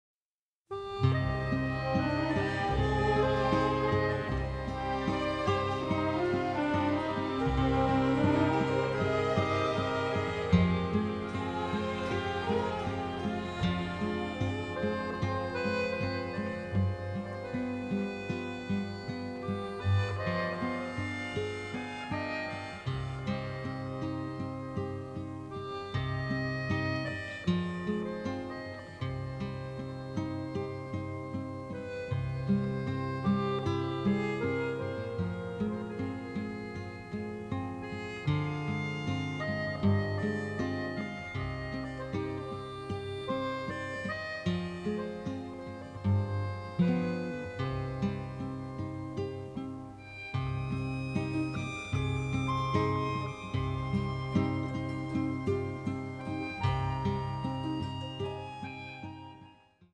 a colorful, bittersweet score
The score also features a bossa, a waltz and a tango.